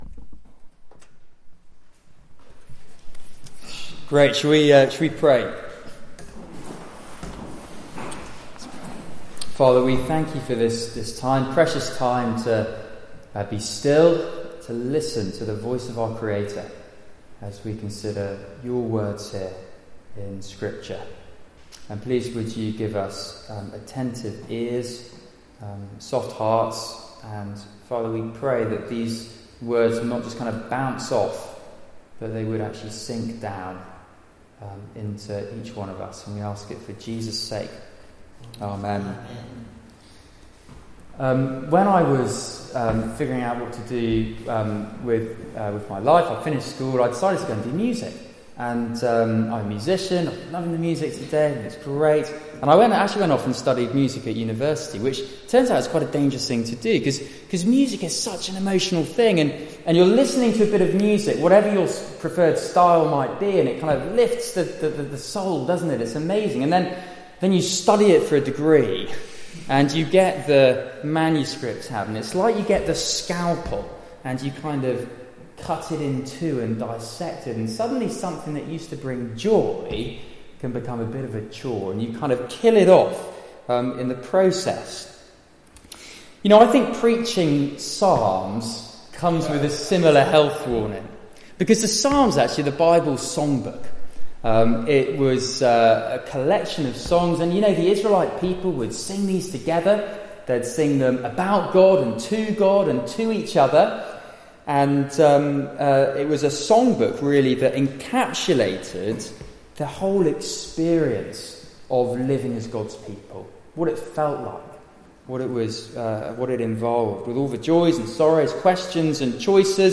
Psalm 1 Service Type: Weekly Service at 4pm Bible Text